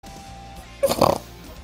sick_snort
snort_C97nijk.mp3